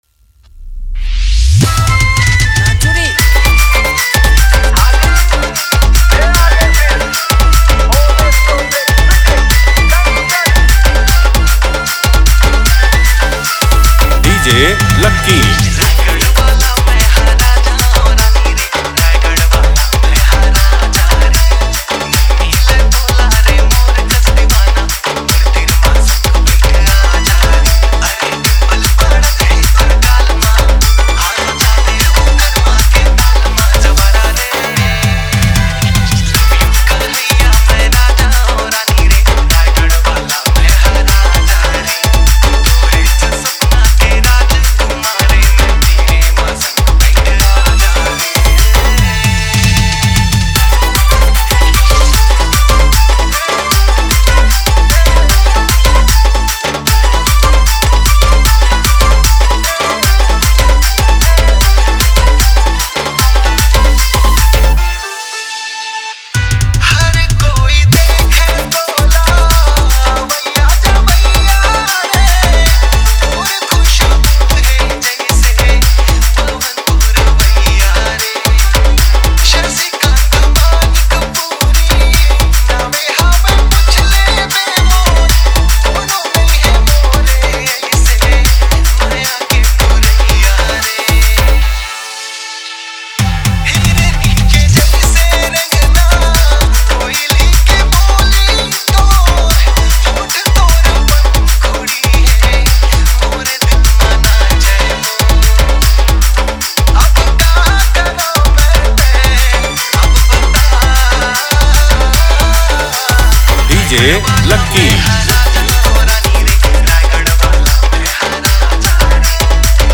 Category: Holi Special Odia Dj Remix Songs